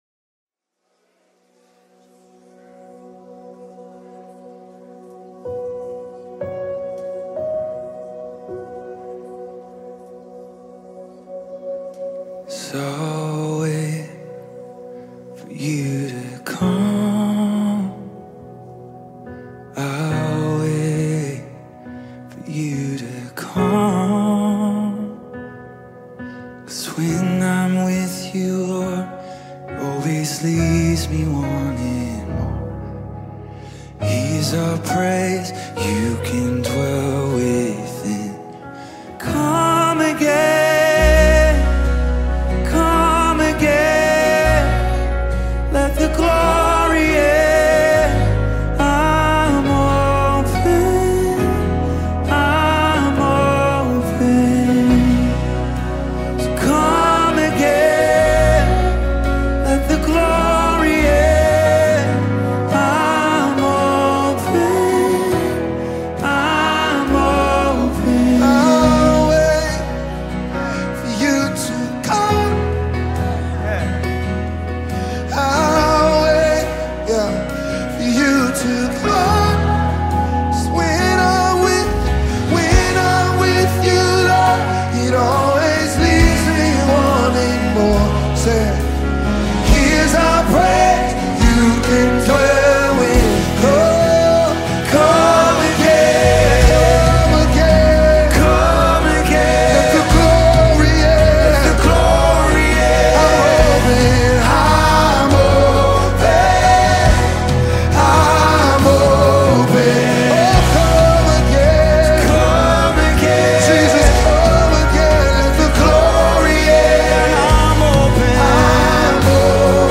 heartfelt worship in this anthemic worship song
powerful-ballad